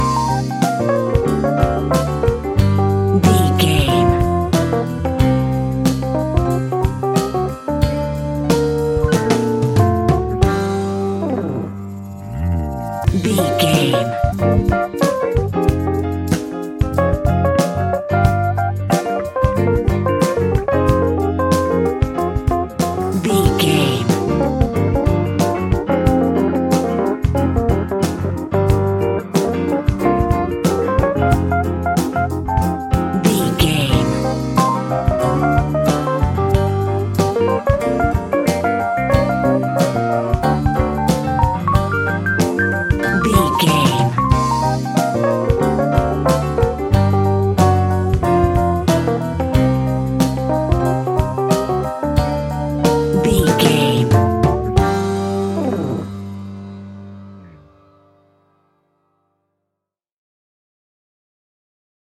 Epic / Action
Fast paced
In-crescendo
Uplifting
Ionian/Major
hip hop